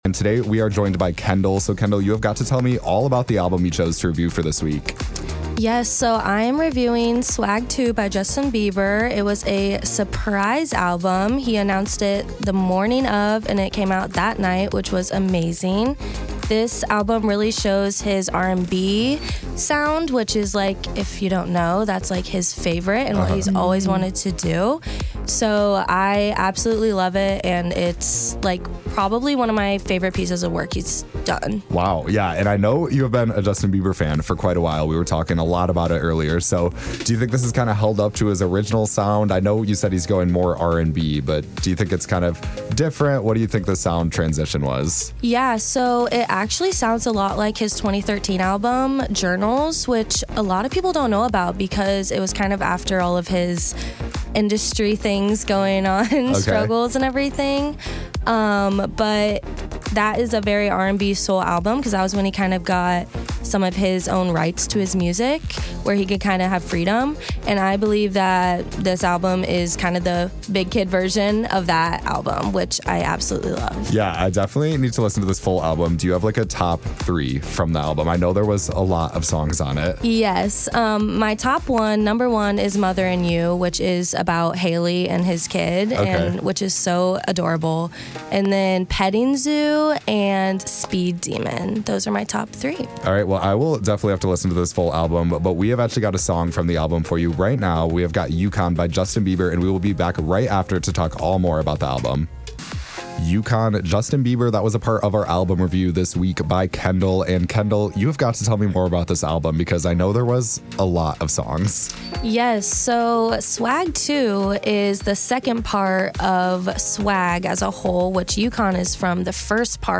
2025 including 23 new songs with his rooted r&b pop sound.